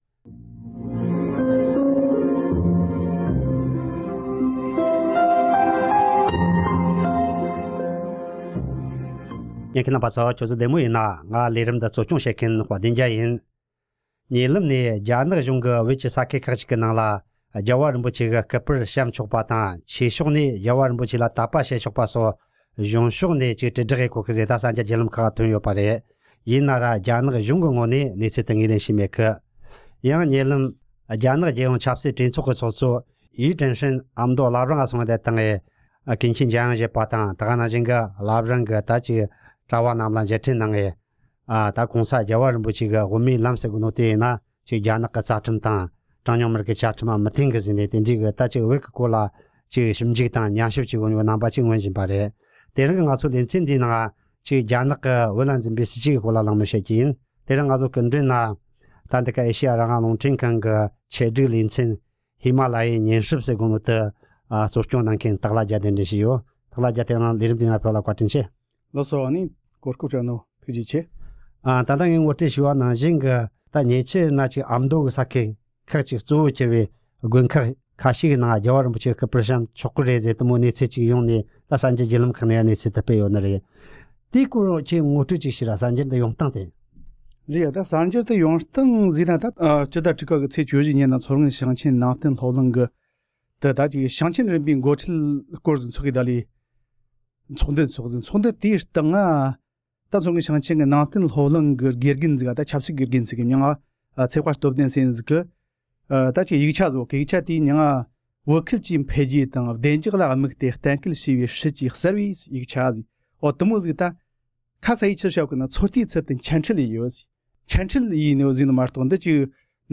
གླེང་མོལ་བྱས་པར་གསན་རོགས་གནང་།།